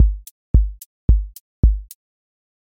QA Test — four on floor
four on floor QA Listening Test house Template: four_on_floor April 17, 2026 ← Back to all listening tests Audio four on floor Open MP3 directly Selected Components macro_house_four_on_floor voice_kick_808 voice_hat_rimshot Test Notes What This Test Is Four on floor Selected Components macro_house_four_on_floor voice_kick_808 voice_hat_rimshot